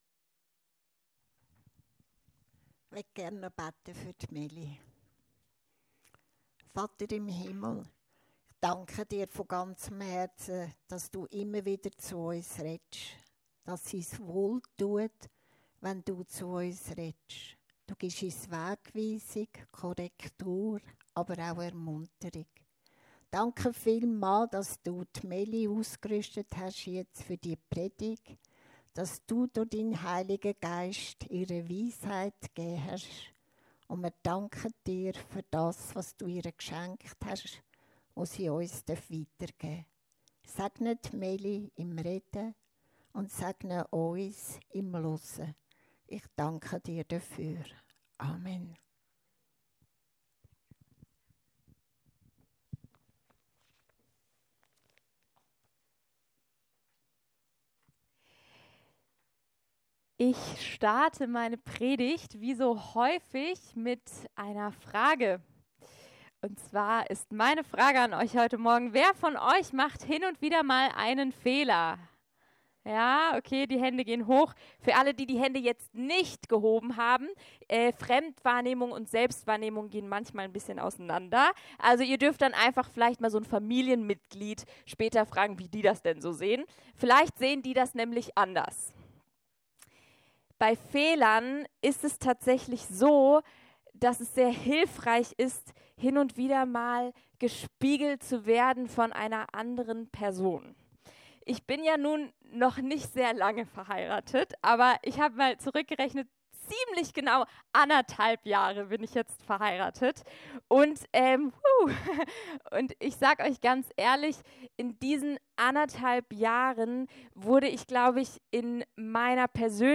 Eine Predigt